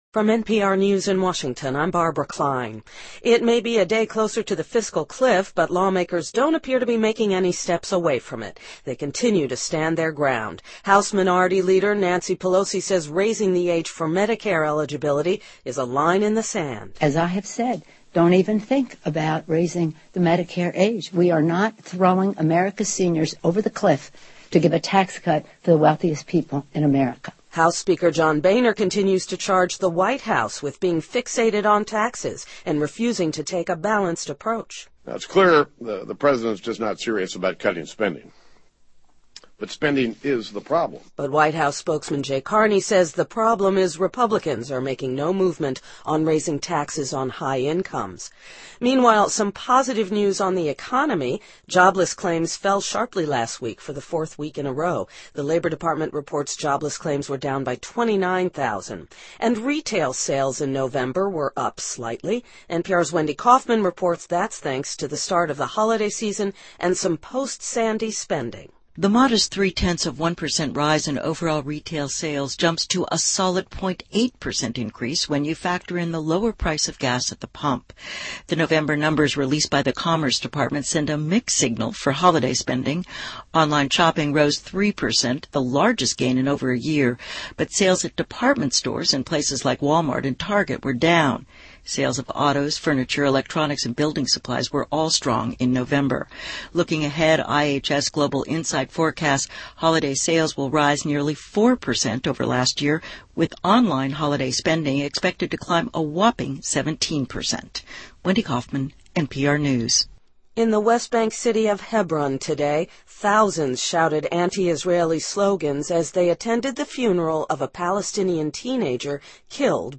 NPR News,2012-12-14